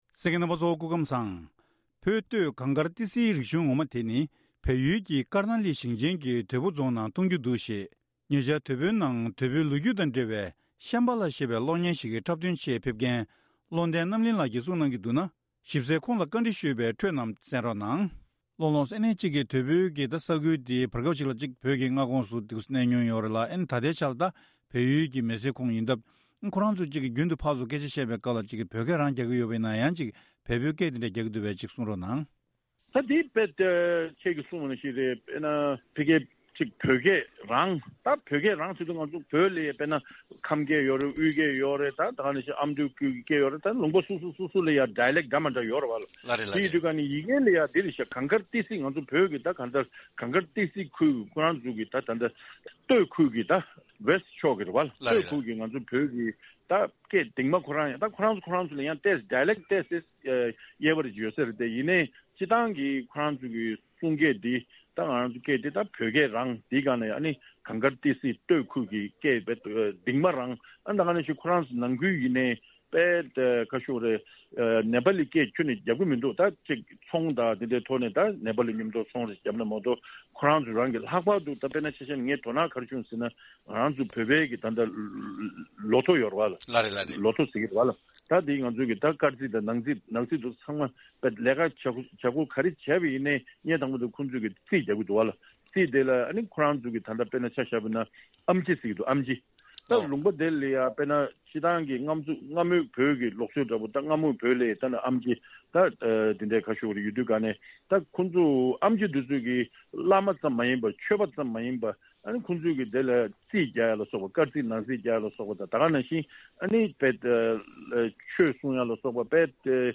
དེ་རིང་གི་བཅར་འདྲིའི་ལེ་ཚན་ནང་།